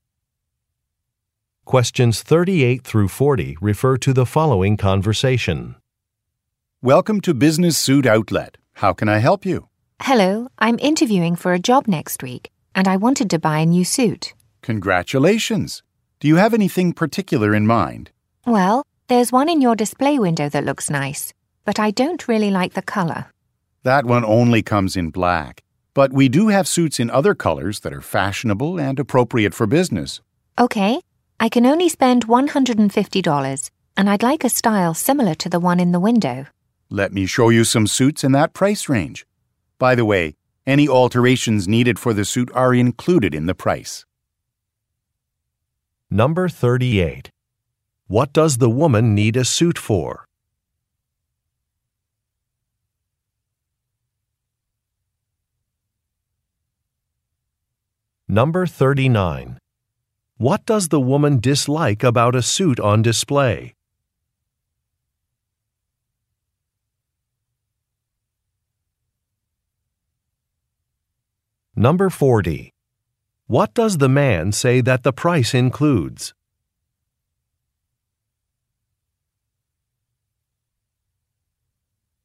Question 38 - 40 refer to following conversation: